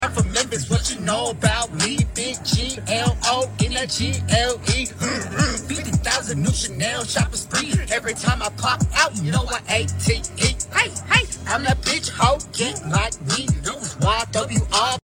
Man singing meme green screen sound effects free download